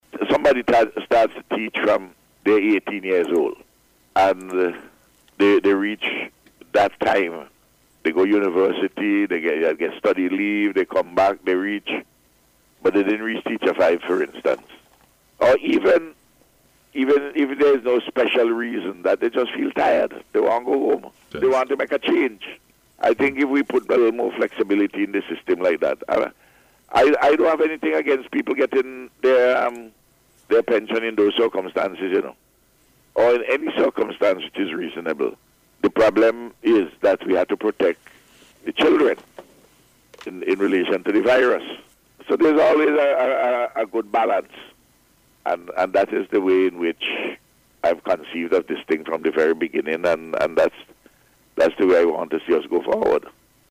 Speaking on NBC Radio on Wednesday the Prime Minister said among them will be the Electronics Communication Bill and the Virtual Assets Bill.